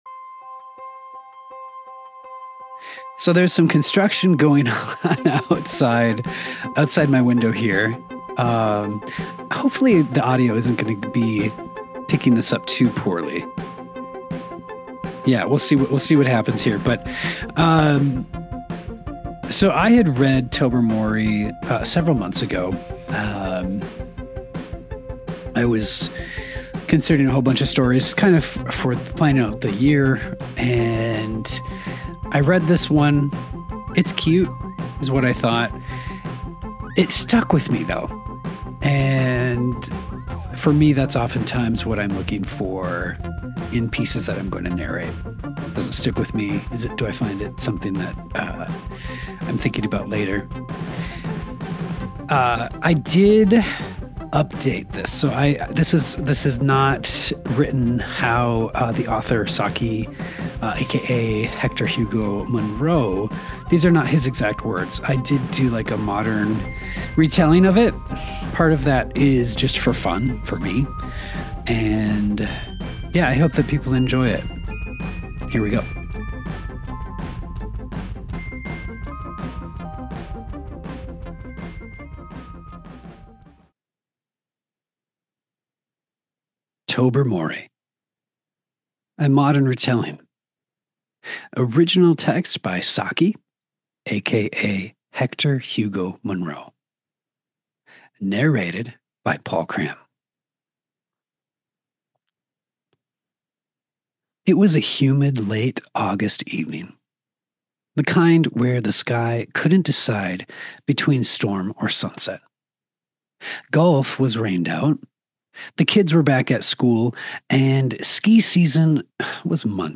The Cat Who Knew Too Much | 🐈‍⬛ Story Time